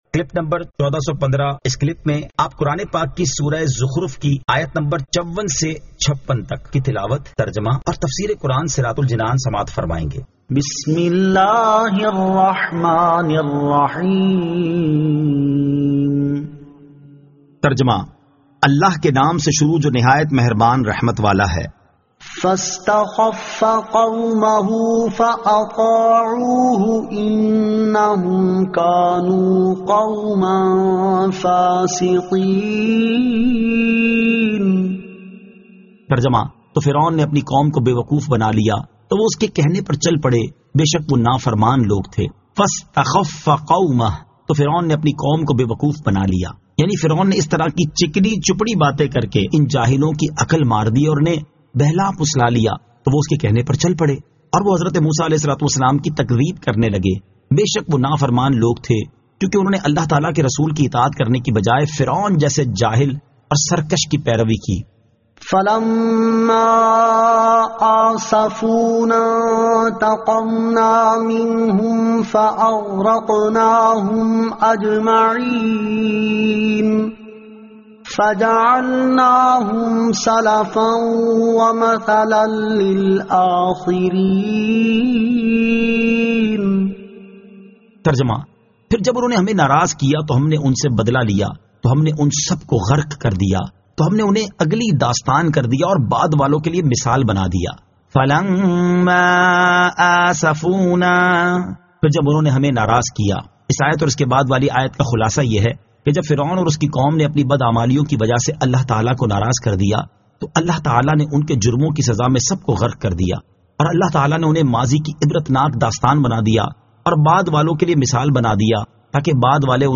Surah Az-Zukhruf 54 To 56 Tilawat , Tarjama , Tafseer